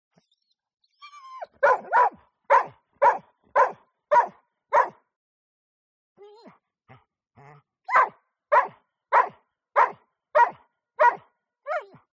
دانلود صدای سگ اهلی از ساعد نیوز با لینک مستقیم و کیفیت بالا
جلوه های صوتی